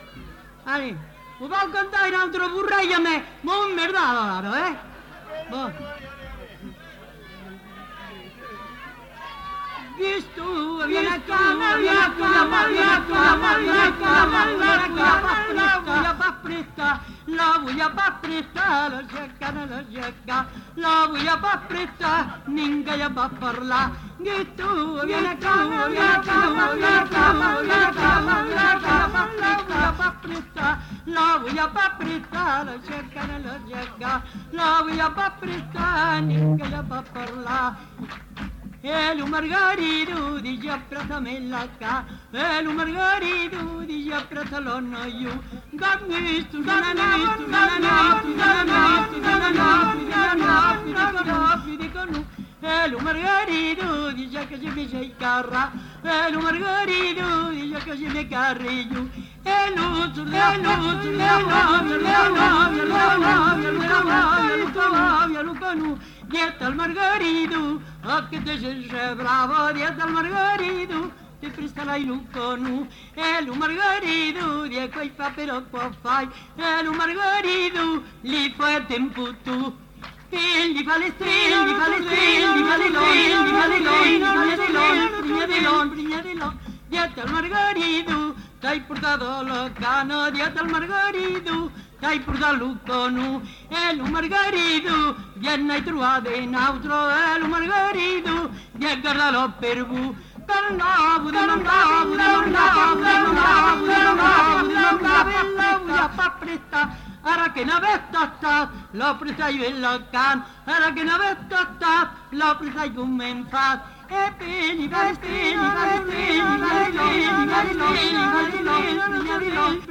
Genre : chanson-musique
Effectif : 1
Type de voix : voix de femme
Production du son : chanté
Instrument de musique : rebec
Danse : bourrée
Classification : danses